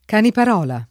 Caniparola [ kanipar 0 la ] top. (Tosc.)